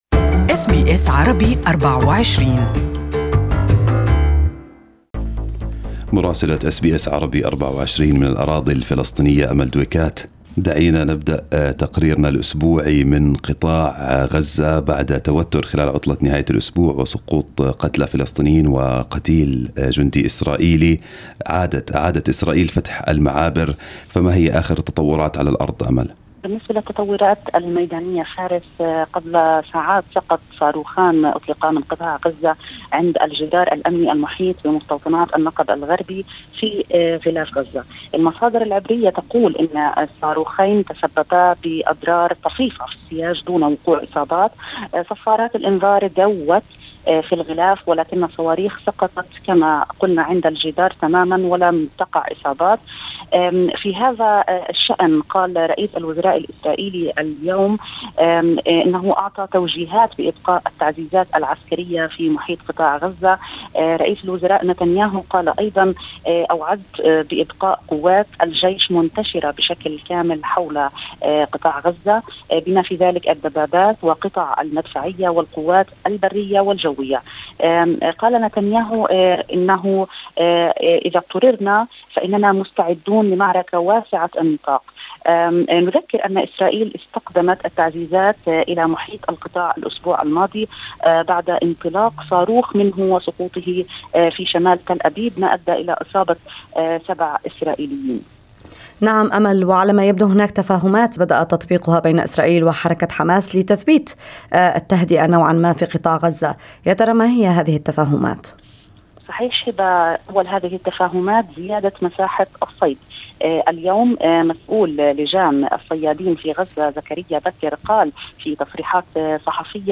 Our correspondent in Ramallah has the details